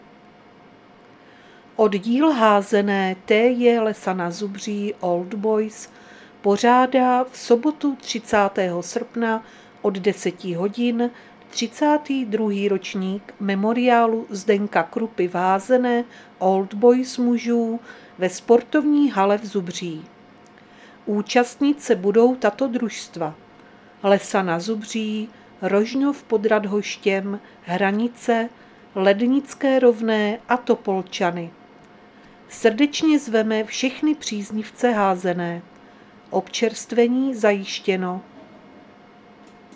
Záznam hlášení místního rozhlasu 28.8.2025
Zařazení: Rozhlas